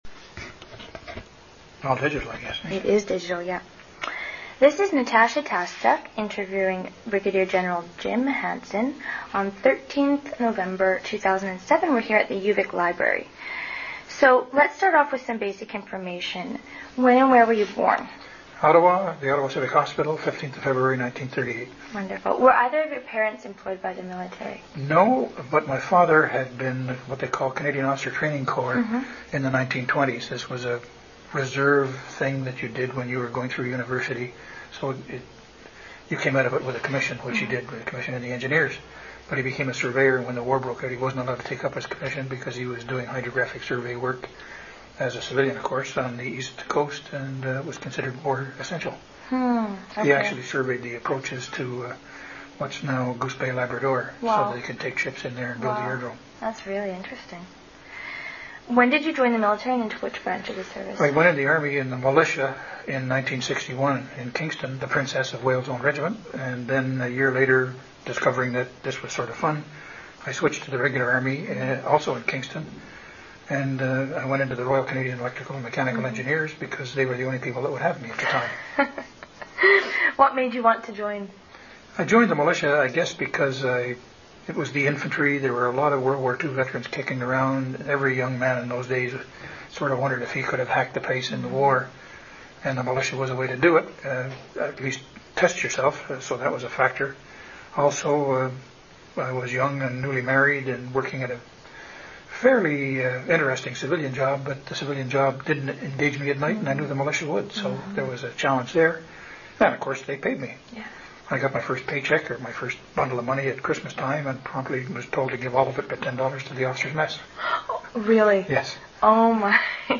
MacPherson Library, Victoria, B.C.